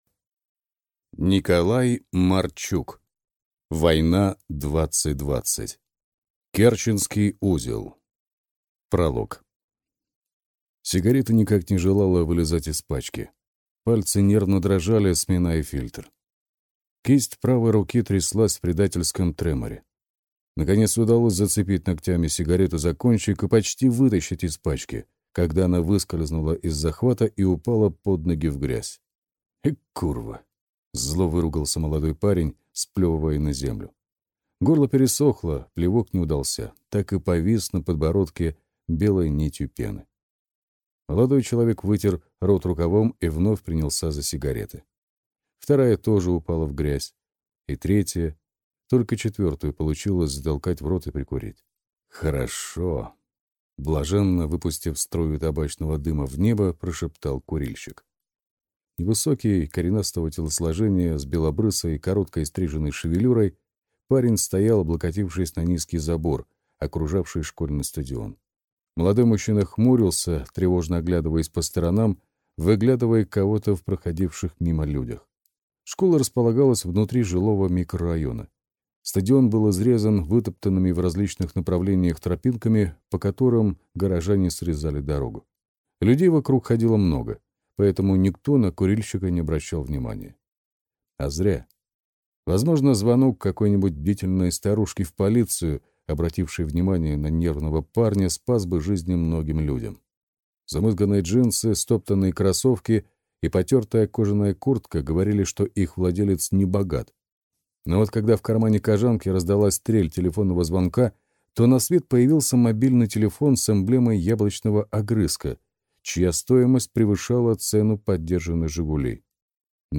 Аудиокнига Война 2020. Керченский узел | Библиотека аудиокниг